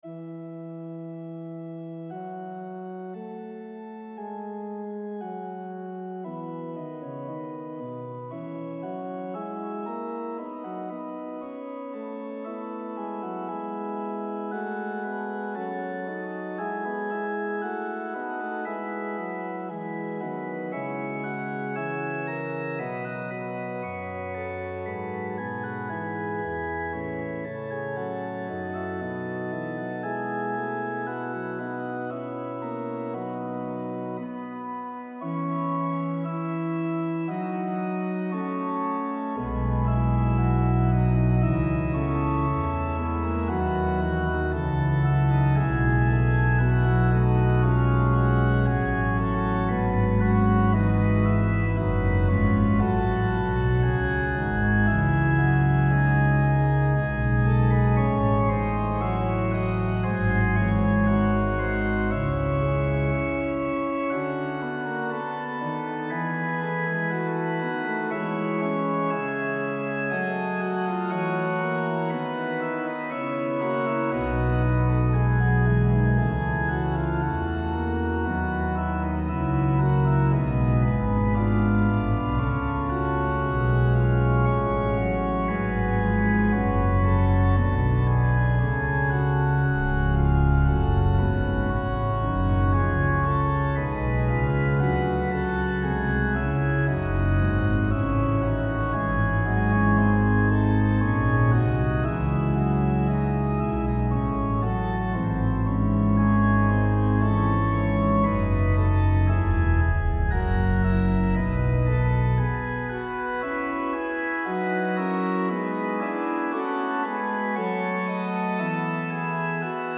arranged for organ
and recorded using Organteq plugin with Logic Pro.